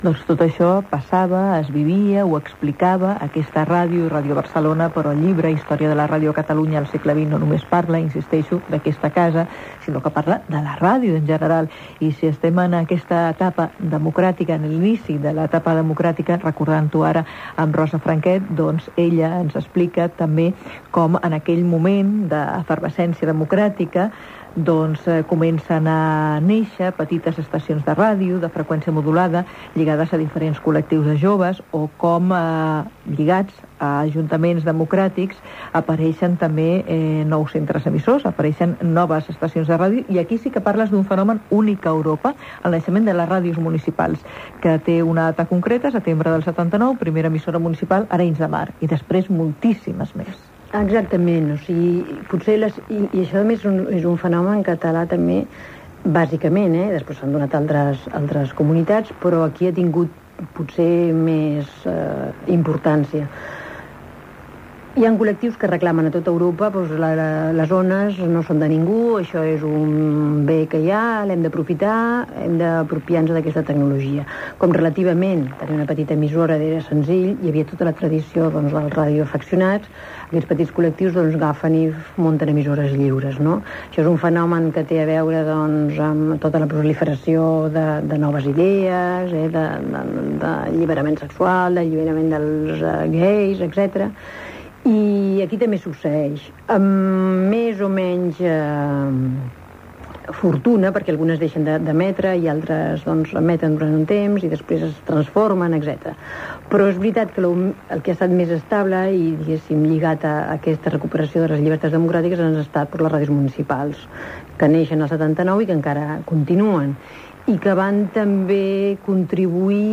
Entrevista
Divulgació